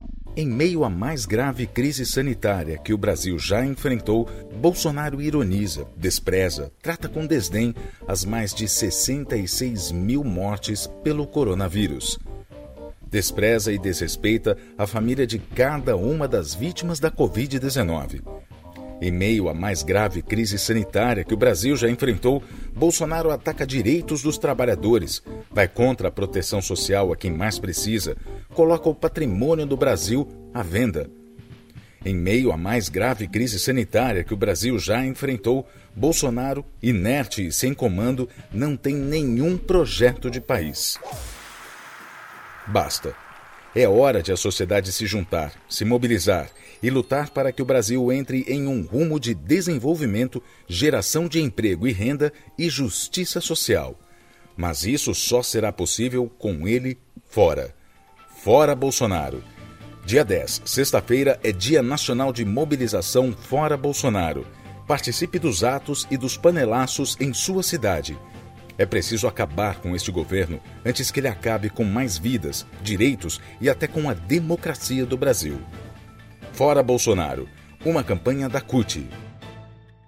Áudio | Spot 1 |